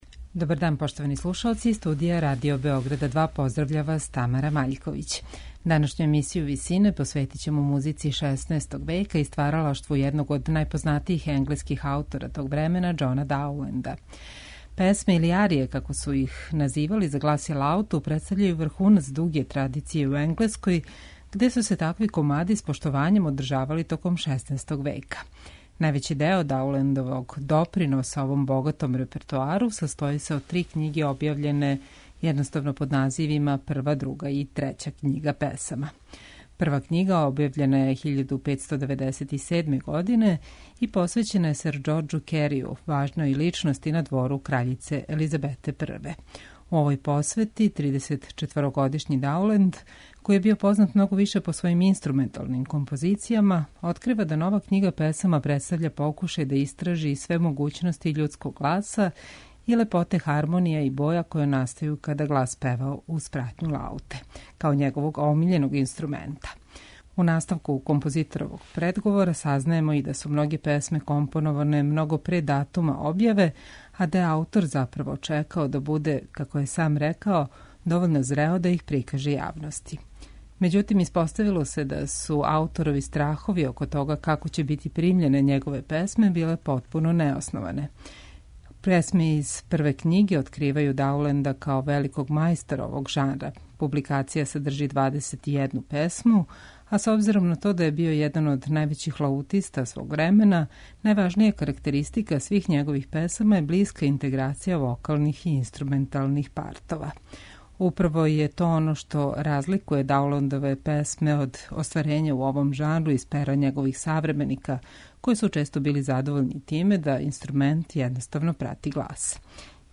У емисији Висине слушаћете песме за тенор и лауту енглеског композитора Џона Дауленда.
Арије за глас и лауту представљају врхунац дуге традиције у Енглеској, где су се такви комади писали и изводили са великим поштовањем током читавог 16. века.
Песме из ове књиге извешће тенор Најџел Роџерс и лаутиста Пол Одет.